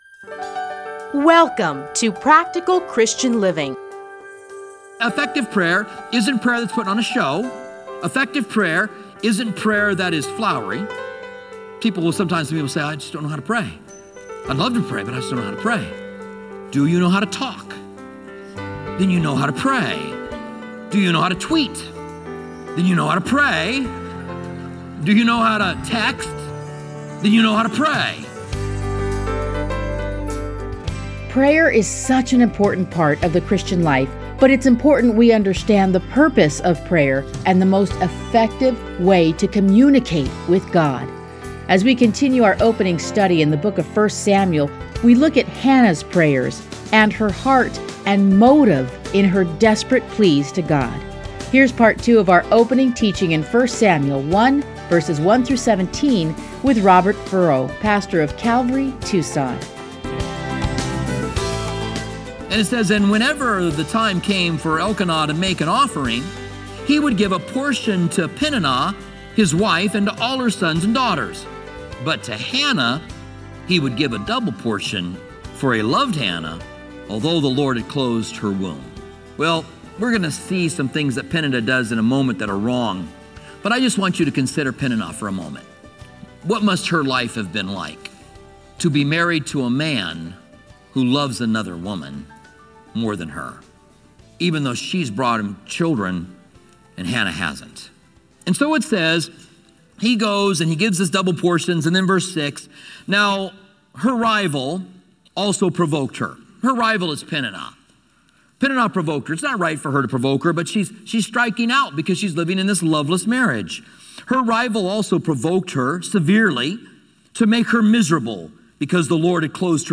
teachings are edited into 30-minute radio programs titled Practical Christian Living. Listen to a teaching from 1 Samuel 1:1-17.